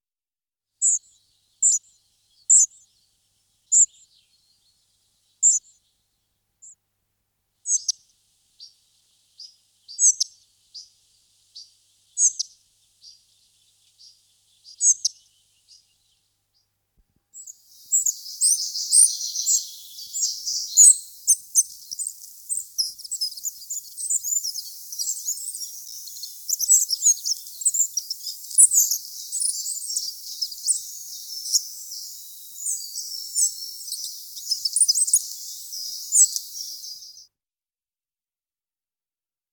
Pigliamosche
Richiamo un alto e stridulo ‘tsii’ o ‘tsrii’. Canto alto e rapido simile a una sequenza di richiami.
Pigliamosche.mp3